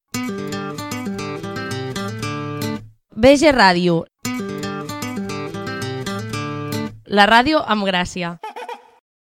Indicatiu de la ràdio
Ràdio per Internet de l'Escola Vedruna de Gràcia.